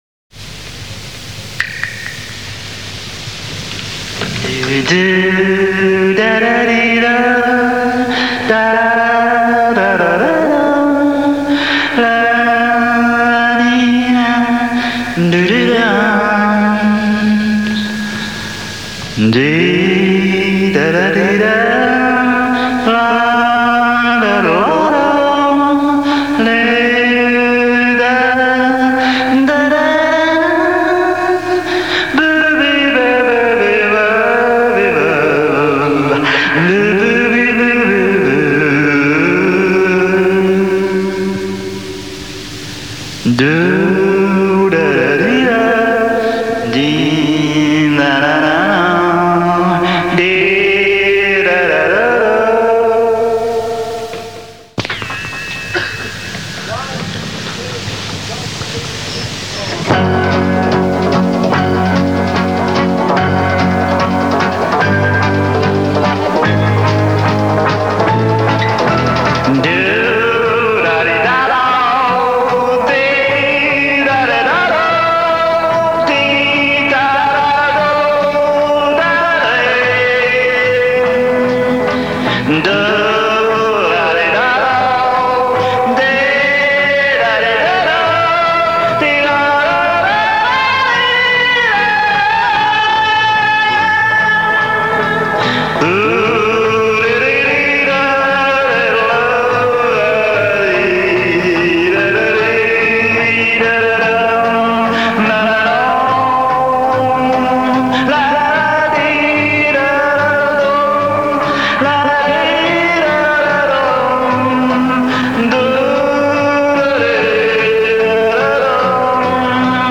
acapella & over backing track